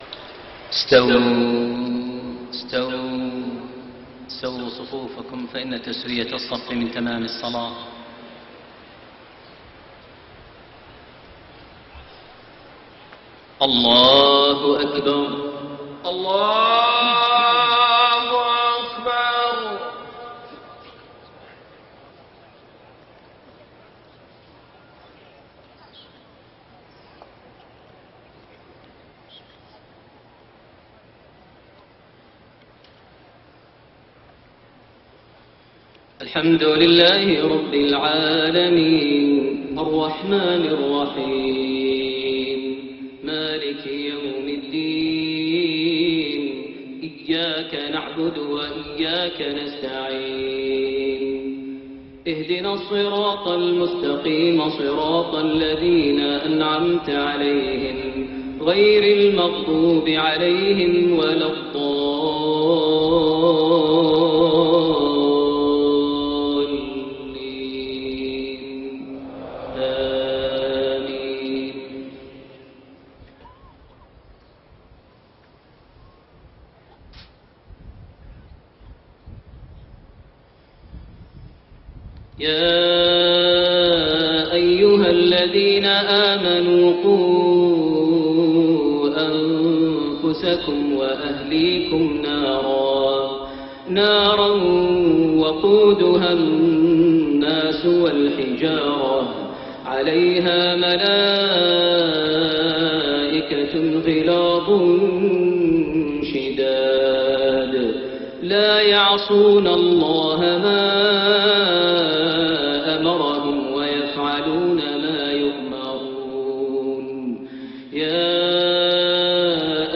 صلاة المغرب 16 رجب 1432هـ خواتيم سورة التحريم 6-12 > 1432 هـ > الفروض - تلاوات ماهر المعيقلي